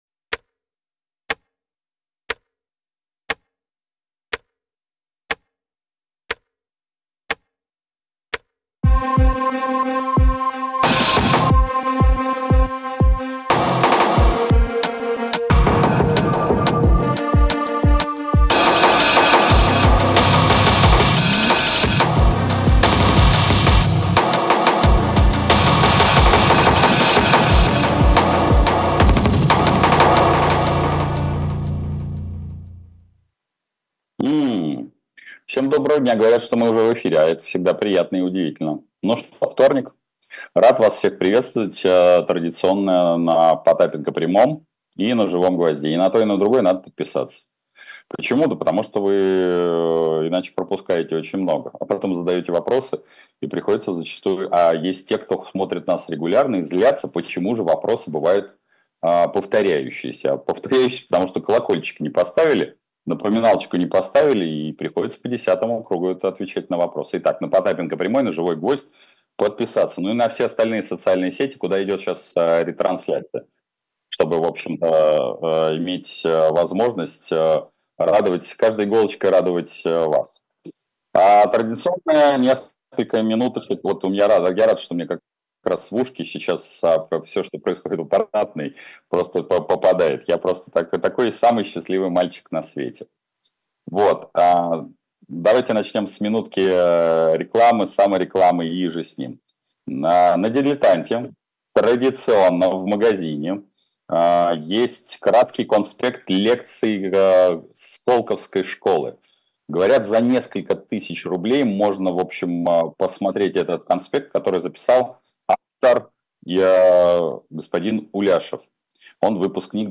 Эфир предпринимателя Дмитрия Потапенко